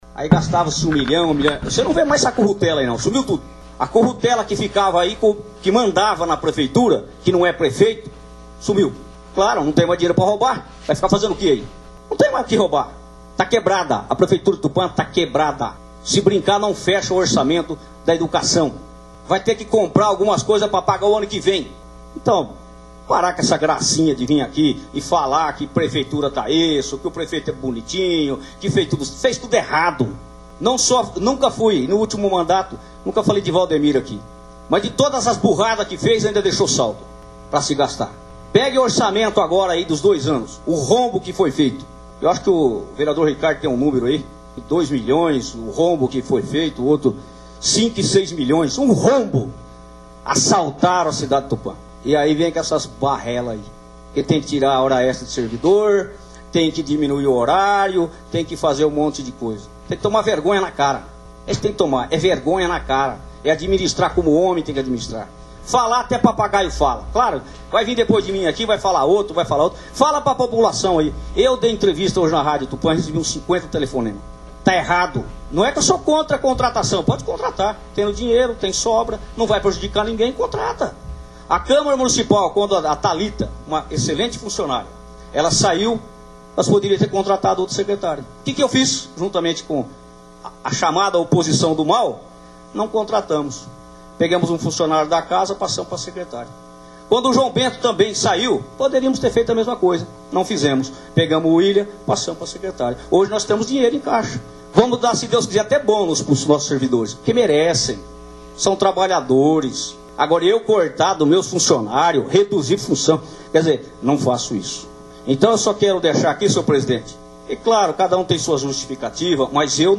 O parlamentar fez uso da palavra em tom de confronto à tese defendida por Ribeirão para justificar a criação de mais 40 cargos em comissão, em momento de crise enfrentada pela prefeitura e com meio expediente apenas (das 7 às 13 horas) desde o dia 13 de outubro e sem data para voltar ao normal.
VALTER ROUBO e ouça áudio do discurso do vereador Valter Moreno